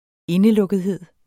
Udtale [ -lɔgəðˌheðˀ ]